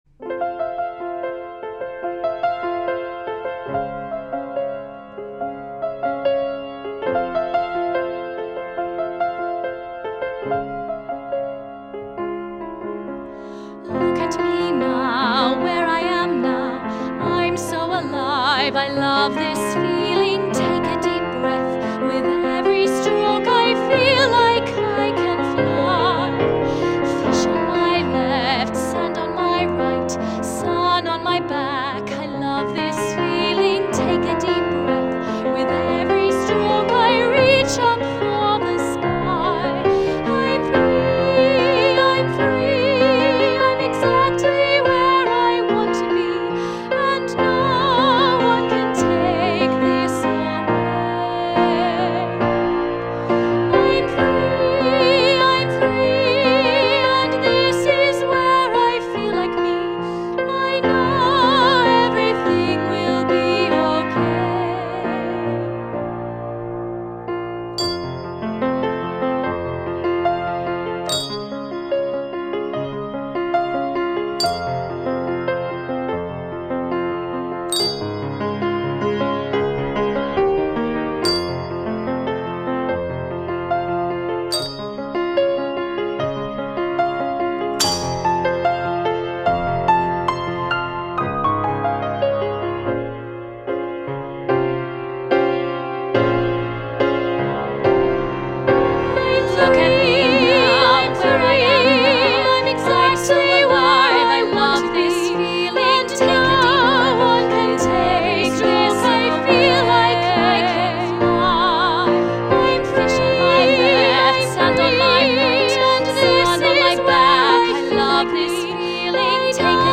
This song with added percussion is all about that joy.
Listen to the live demo below.
SA choir, bells, cymbal and piano